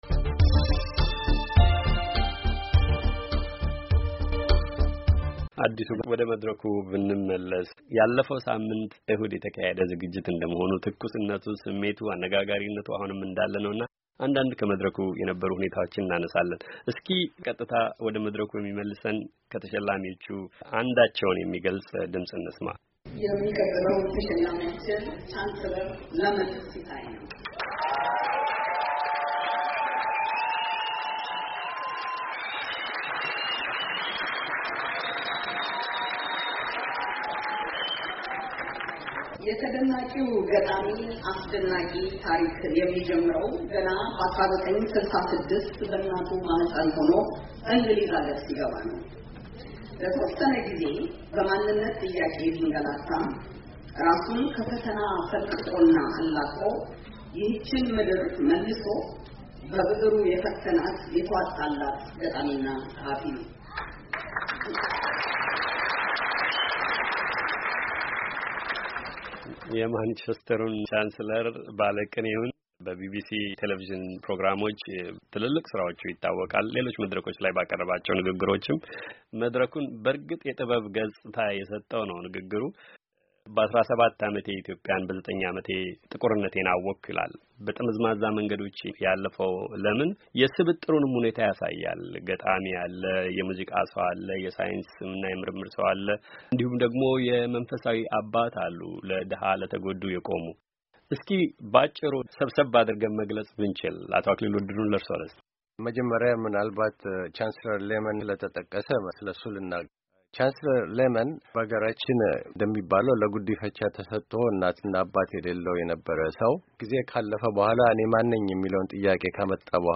ቃለ-ምልልስ